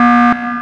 ALARM_Submarine_Fast_loop_stereo.wav